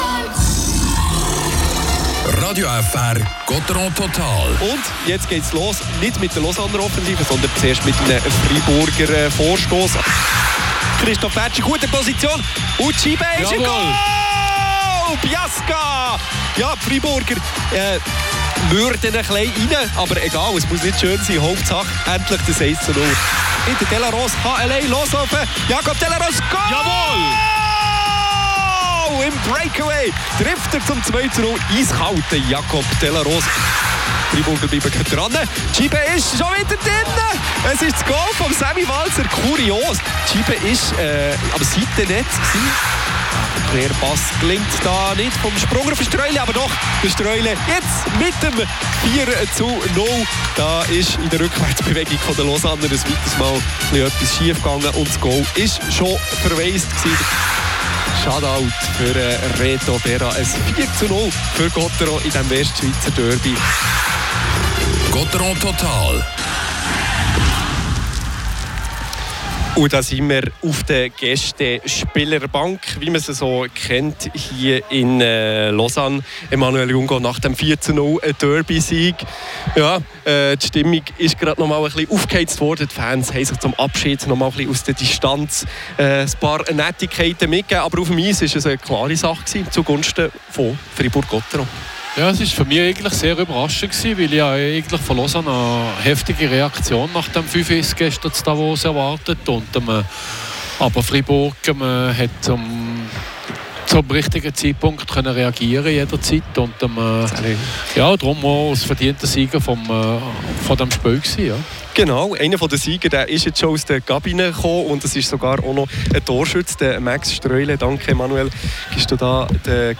Die Highlights des Spiels, die Interviews